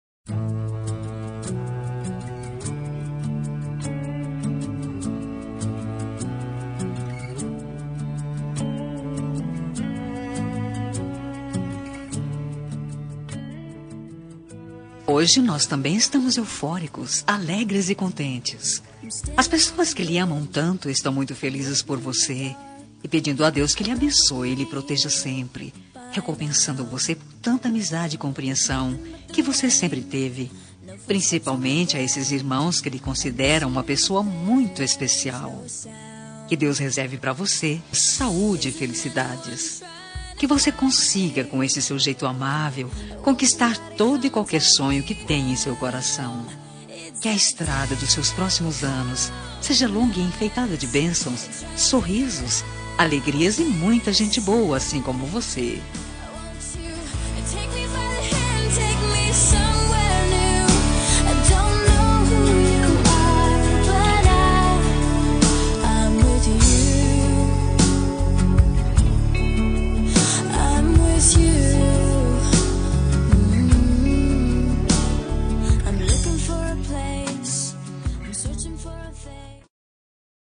Homenagem ao Irmão – Voz Feminina – Cód: 311